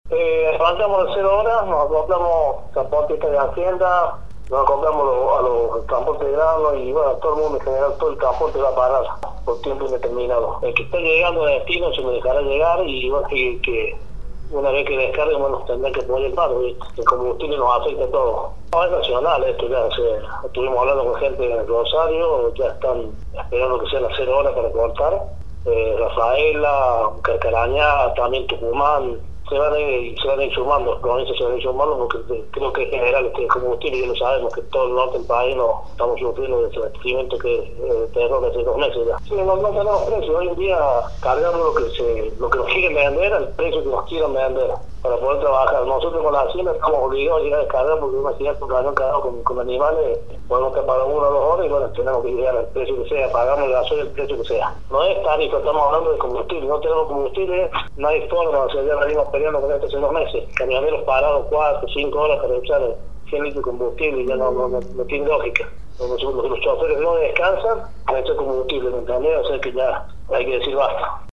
Transportista de hacienda anticipa que el paro es a nivel nacional